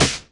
RA_El_Tigre_atk_clean_002.wav